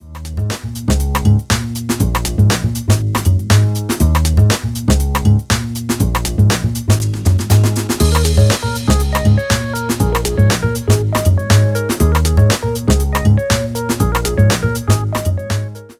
In this example I have simply cut a section from the main drum loop, repeated it in 16th of a bar increments over half a bar and used level automation to fade the whole thing in.
This is obviously quite a laid back track so intensity isn’t really called for but in other styles these builds could be used over larger sections of the track.
The finished build.